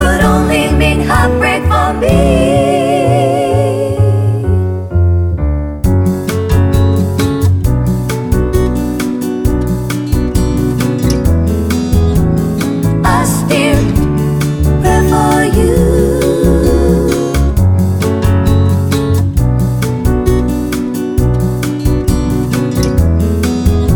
no Backing Vocals Soul / Motown 3:36 Buy £1.50